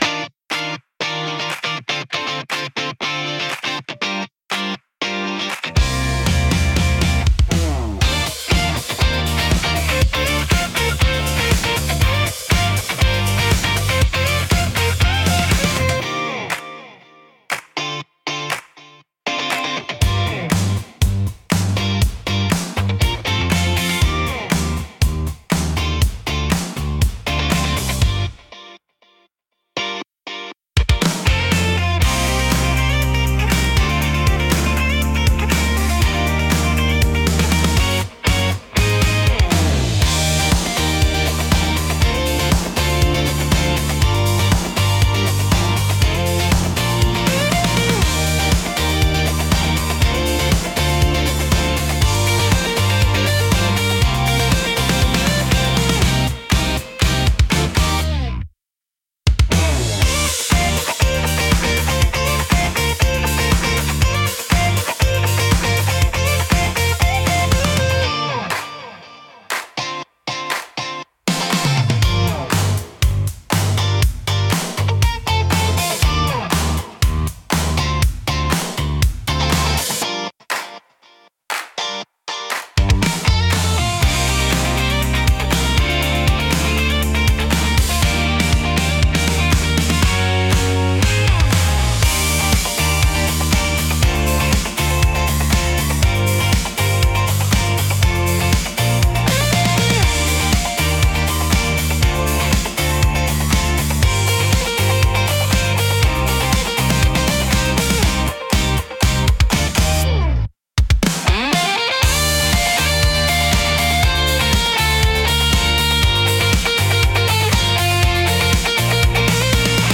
感情の高まりやポジティブな気分を引き出しつつ、テンポ良く軽快なシーンを盛り上げる用途が多いです。